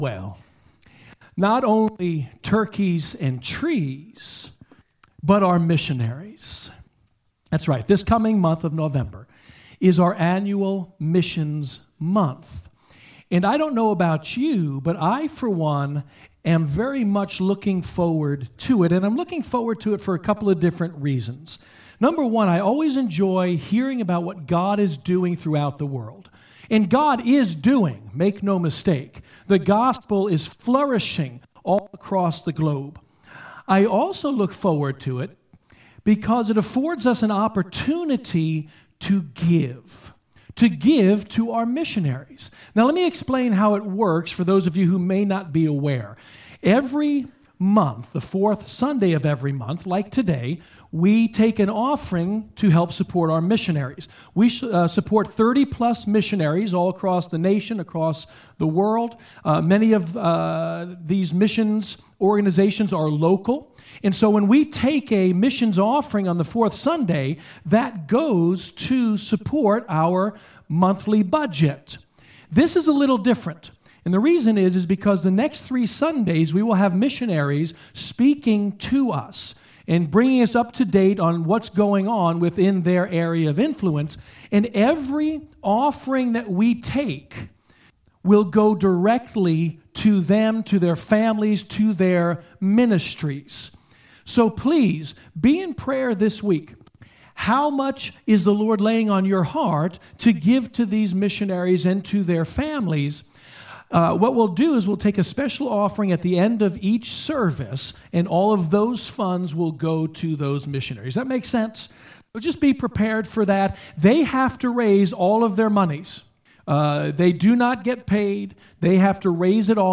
Sermons & Media - Emmanuel Assembly of God